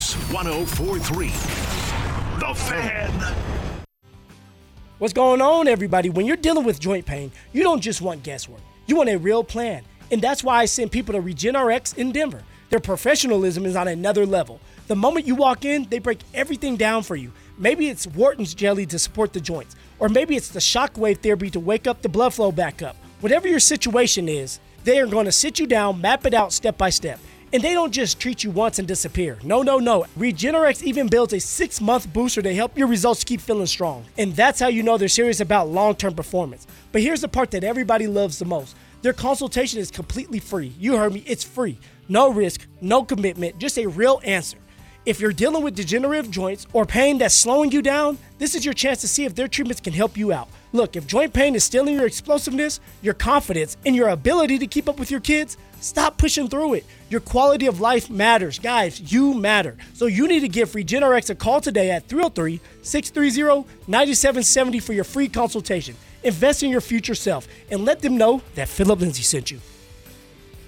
You can hear Phillip sharing his RegenRx experience every day on 104.3 The Fan, where he talks about the power of our non-surgical treatments.
Former NFL Running Back & Denver’s own Phillip Lindsay on Denver Sports 104.3 The Fan